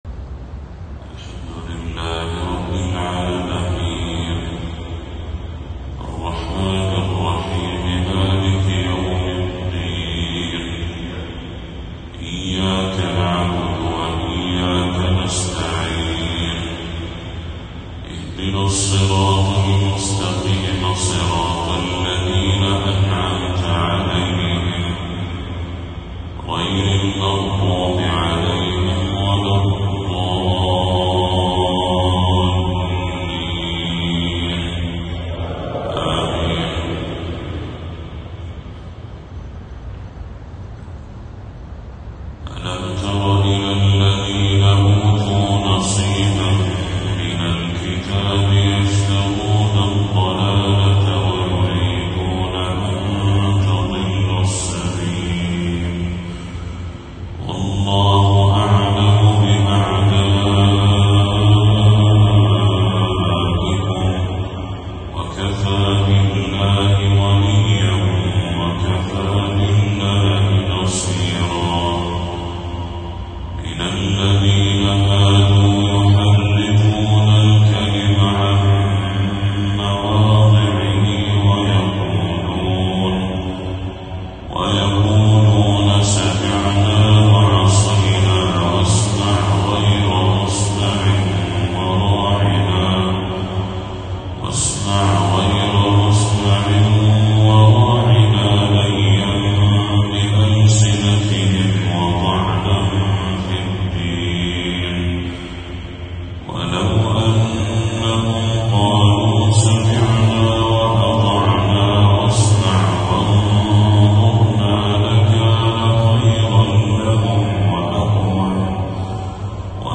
تلاوة من سورة النساء للشيخ بدر التركي | فجر 9 صفر 1446هـ > 1446هـ > تلاوات الشيخ بدر التركي > المزيد - تلاوات الحرمين